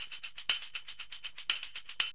TAMB LP 120.wav